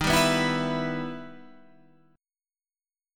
Em/D# Chord